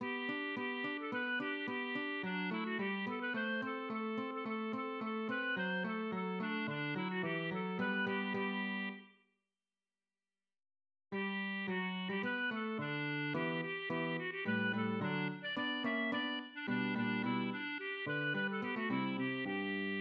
Nursery rhyme
Lyrics and melody